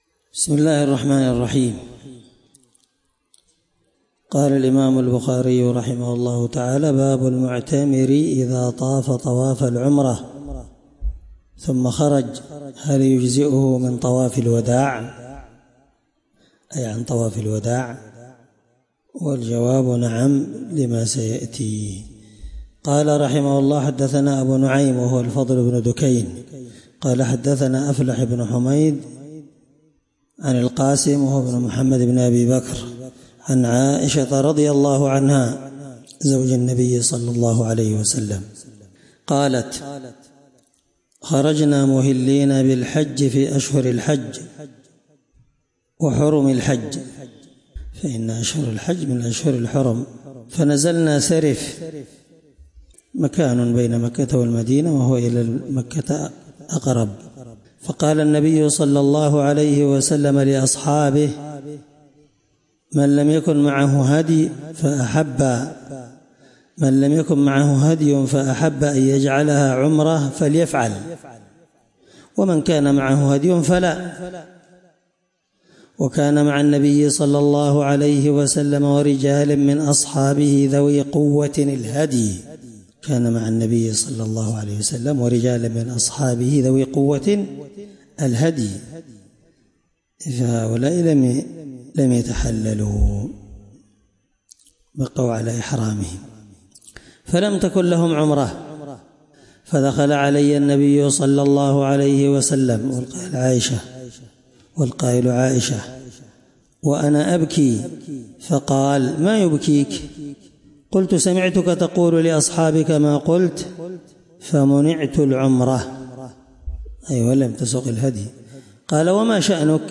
الدرس 7من شرح كتاب العمرة حديث رقم(1788)من صحيح البخاري